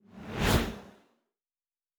pgs/Assets/Audio/Sci-Fi Sounds/Movement/Fly By 01_3.wav at master
Fly By 01_3.wav